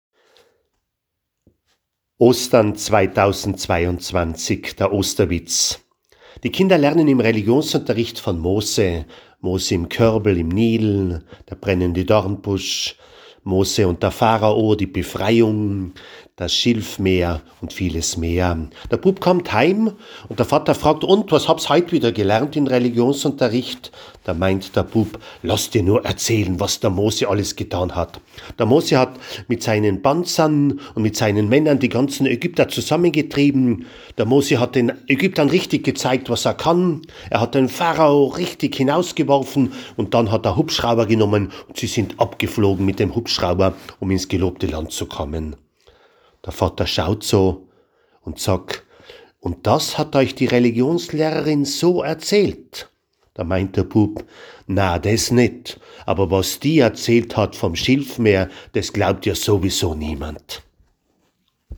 Osterwitz-2022-Osternacht.mp3